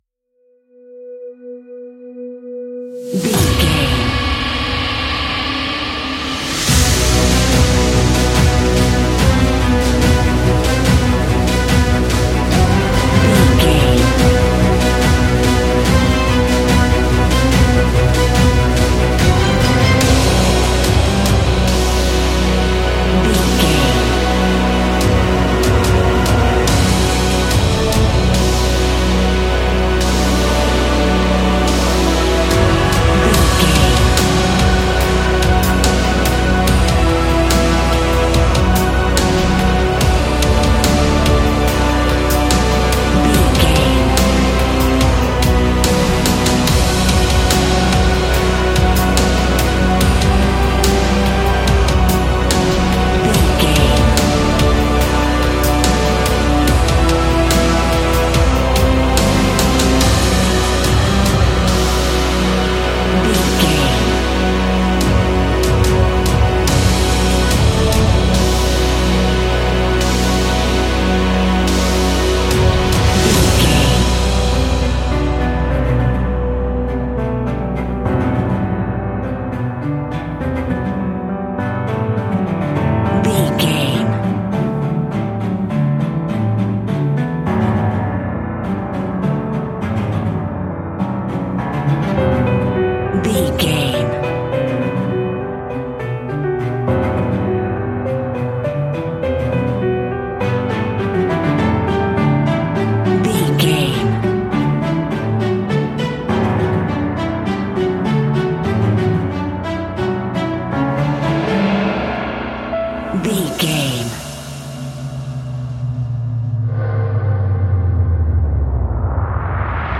Epic / Action
Fast paced
In-crescendo
Aeolian/Minor
F♯
Fast
percussion